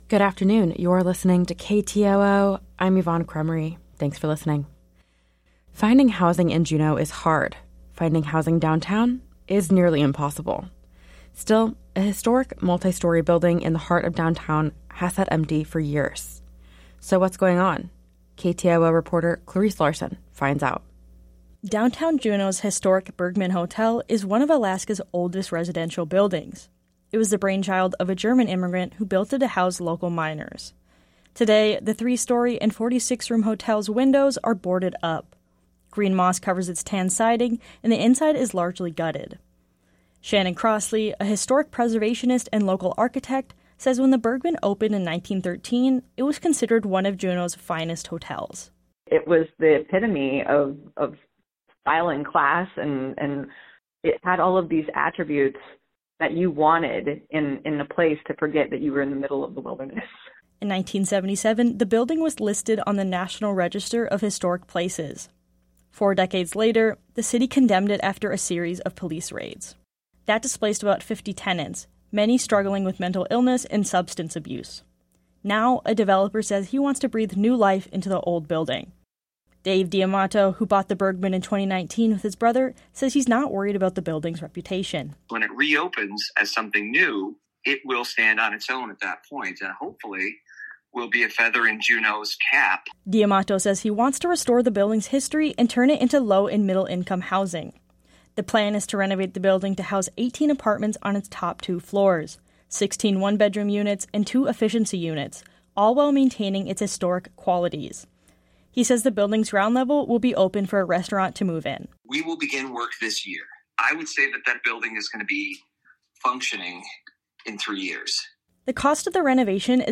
Newscast – Thursday, Jan. 18. 2024